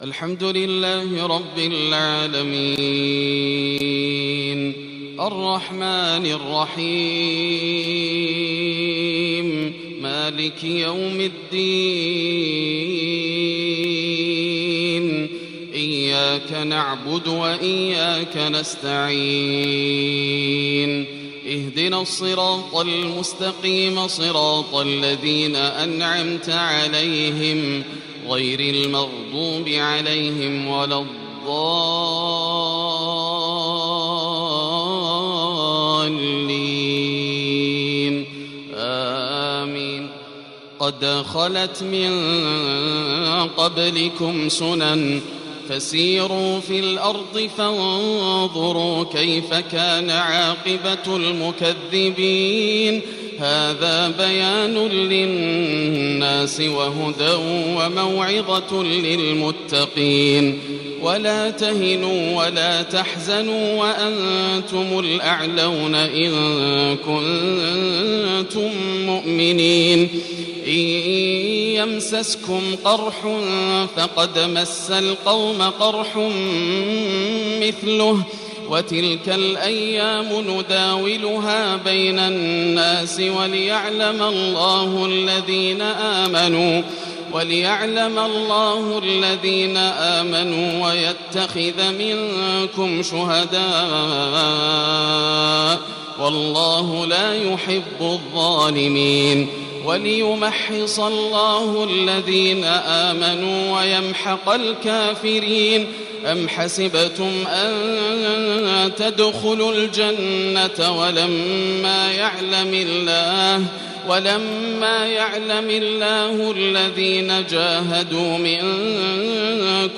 صلاة المغرب الأحد 4-1-1442| من سورة آل عمران | Maghrib prayer Surat Al Imran | 23/8/2020 > 1442 🕋 > الفروض - تلاوات الحرمين